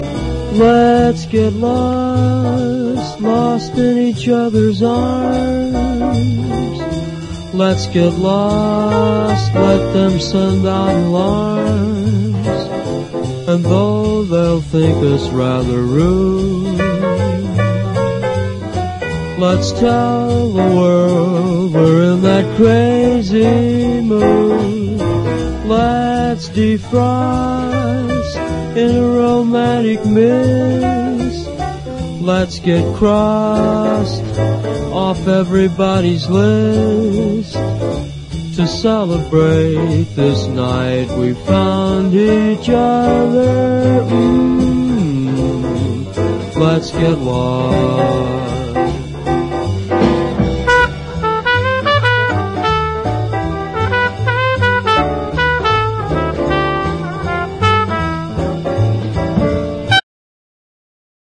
JAZZ / DANCEFLOOR / PIANO
スピーディーに駆け抜ける高速ダンサー
ダンサブルなラテン・テイスト